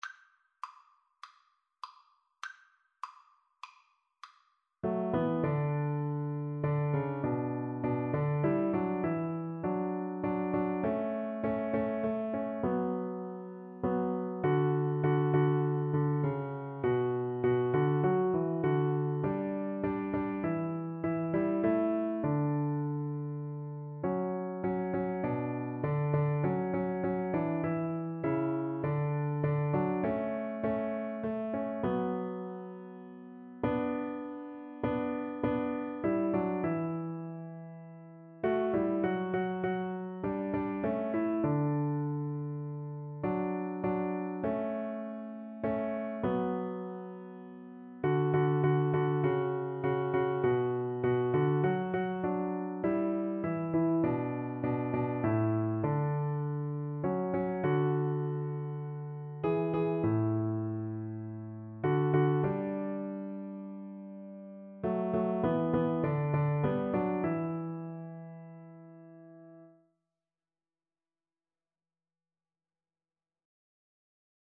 Free Sheet music for Piano Four Hands (Piano Duet)
4/4 (View more 4/4 Music)